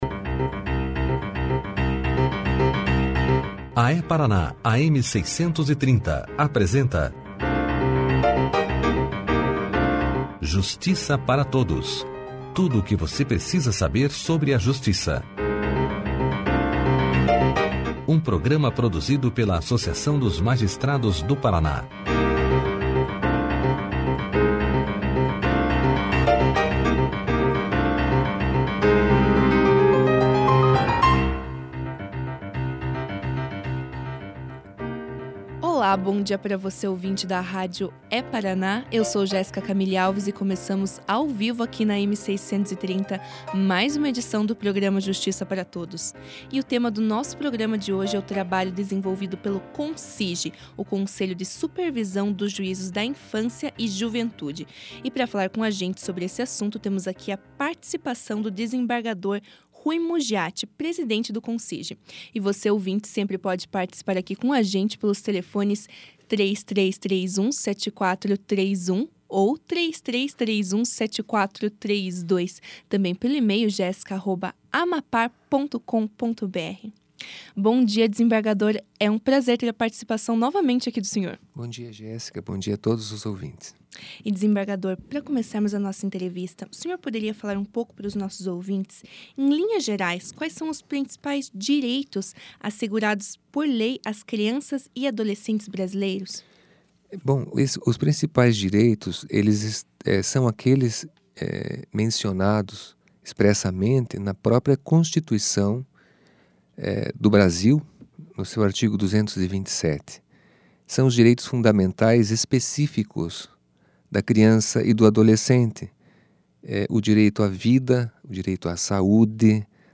No programa Justiça Para Todos dessa quinta-feira (01), o desembargador Ruy Mugiatti levou maiores informações aos ouvintes da rádio É-Paraná, sobre o trabalho realizado pelo CONSIJ, o Conselho de Supervisão dos Juízos da Infância e da Juventude.